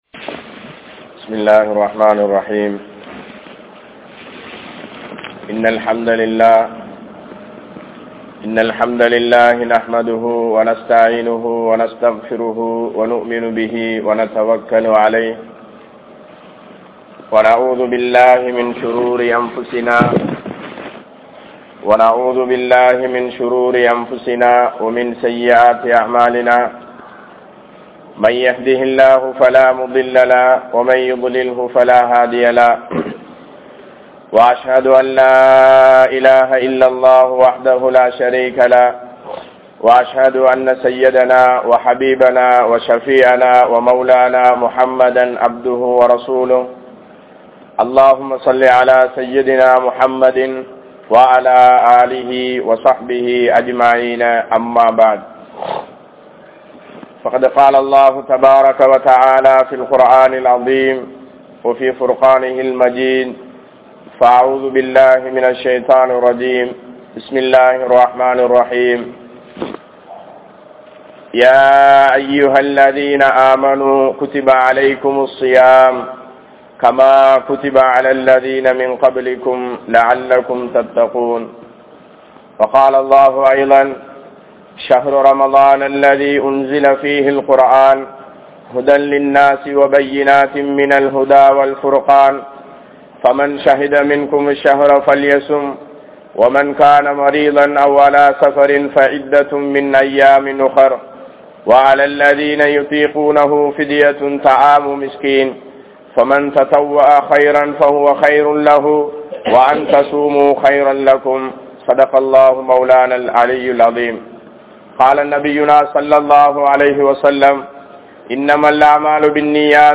Noanpu Ean Kadamaiyaanathu? (நோன்பு ஏன் கடமையானது?) | Audio Bayans | All Ceylon Muslim Youth Community | Addalaichenai
Gallella Jumua Masjidh